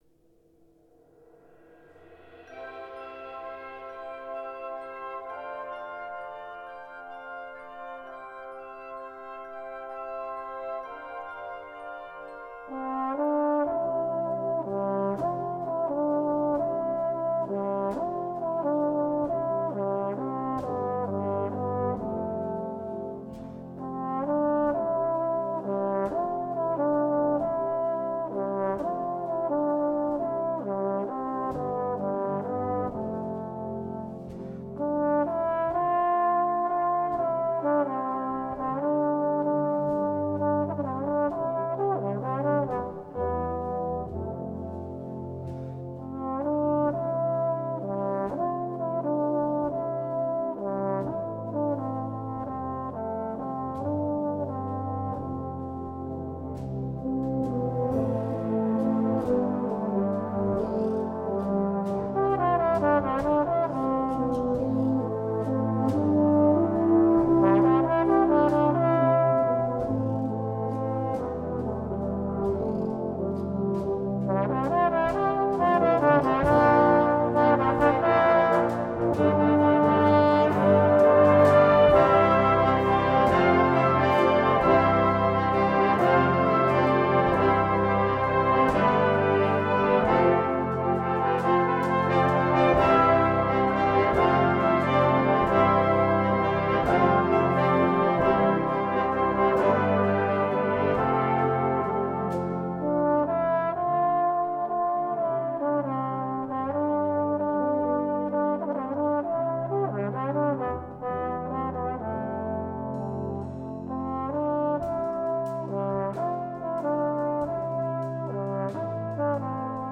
Besetzung: Trombone Solo & Brass Band